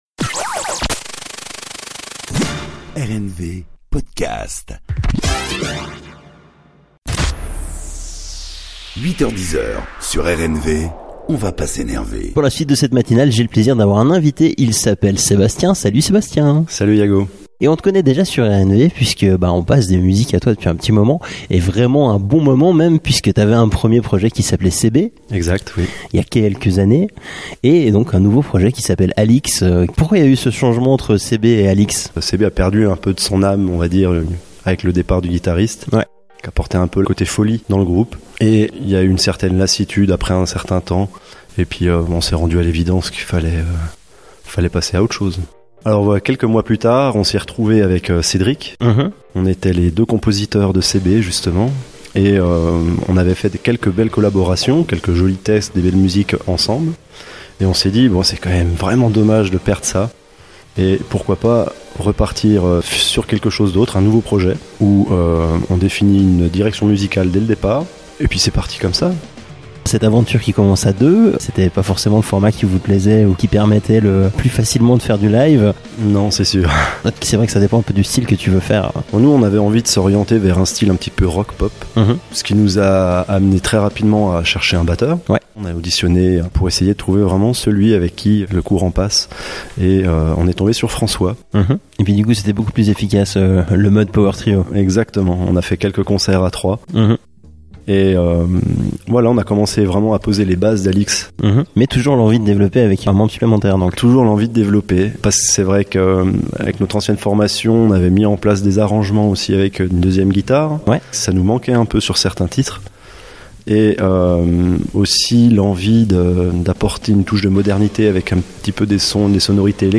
Interviews radio